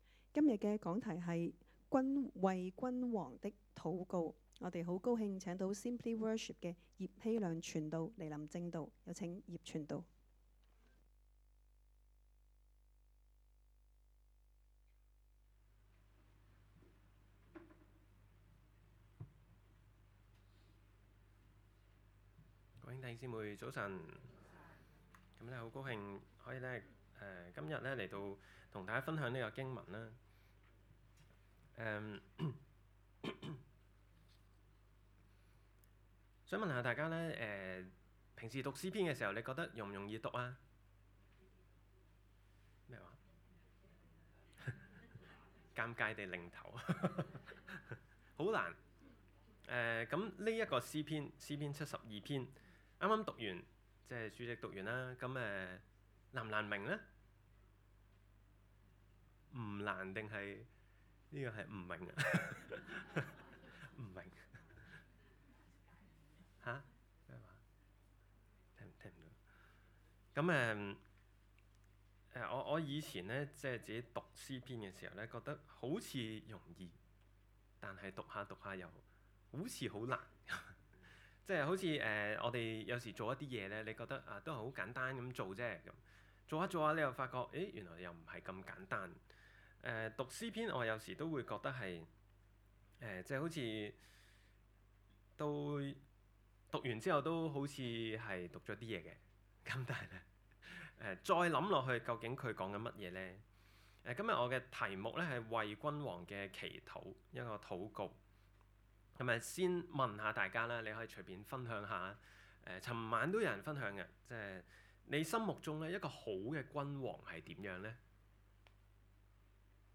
講道 ：為「君王」的禱告